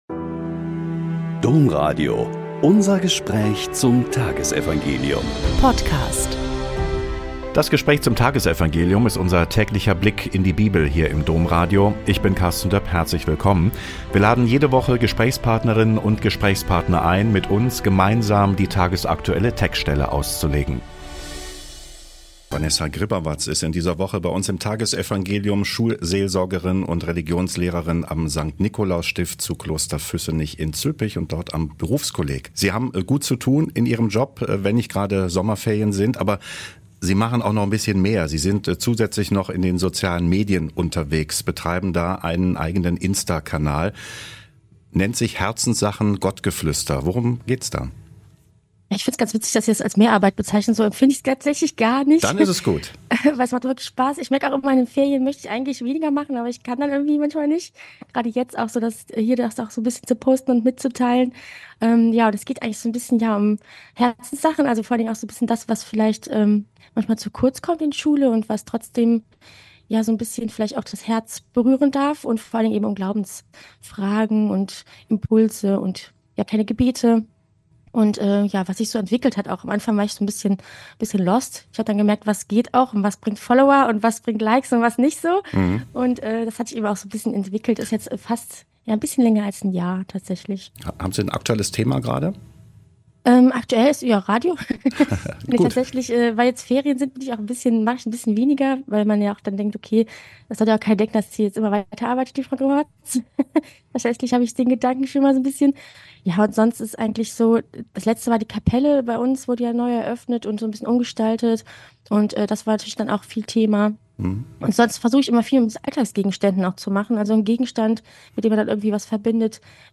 Mt 11,28-30 - Gespräch